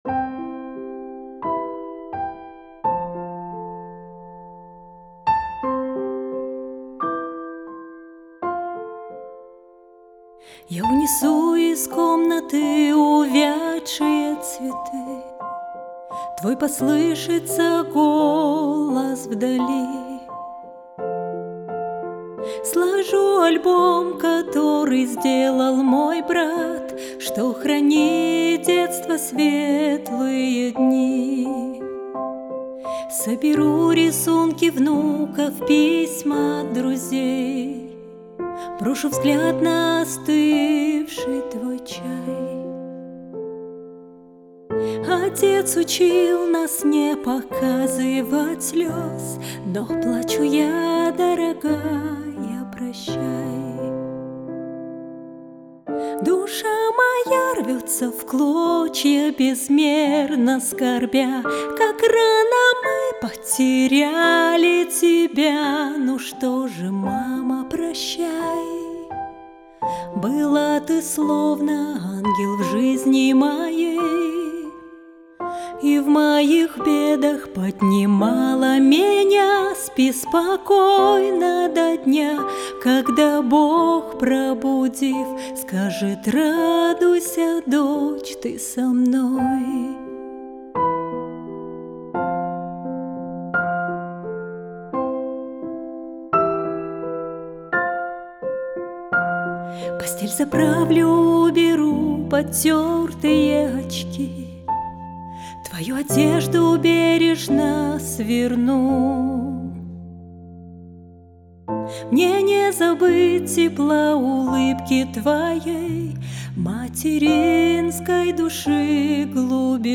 песня
301 просмотр 406 прослушиваний 13 скачиваний BPM: 86